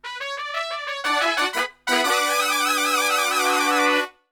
FUNK3 B M.wav